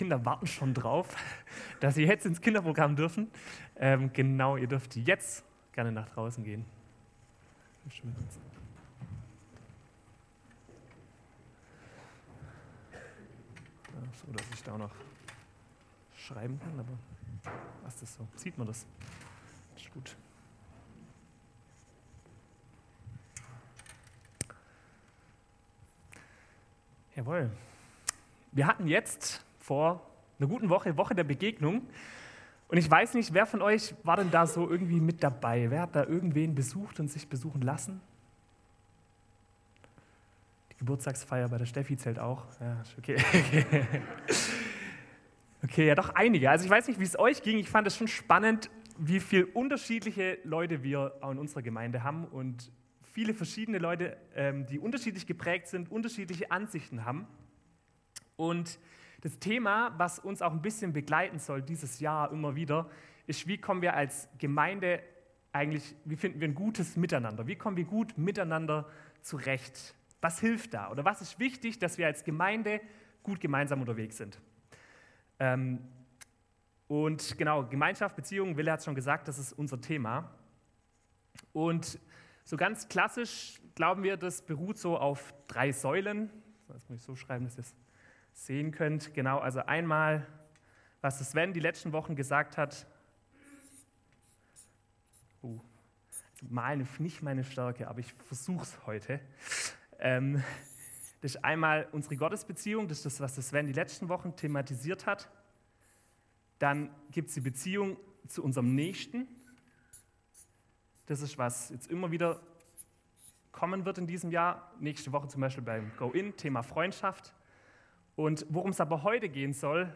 Gottesdienst am 11.02.2024